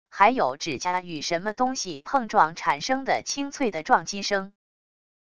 还有指甲与什么东西碰撞产生的清脆的撞击声wav音频